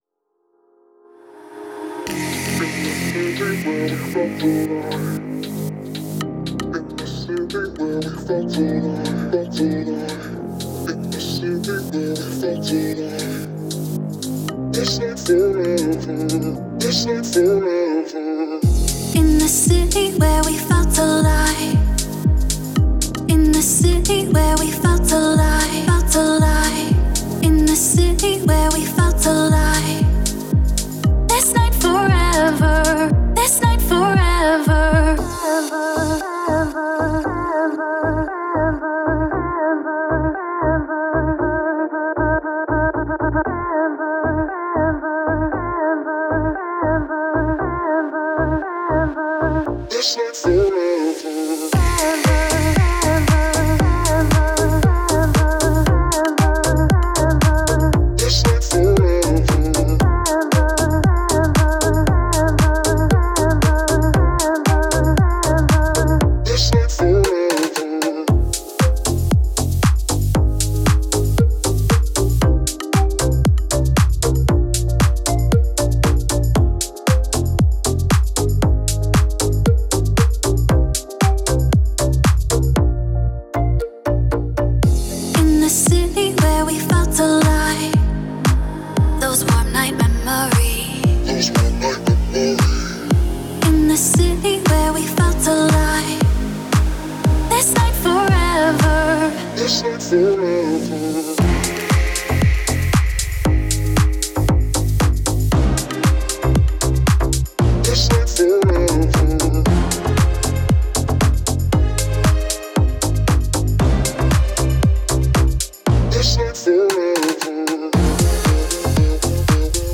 это мощная рок-композиция, наполненная энергией и эмоциями.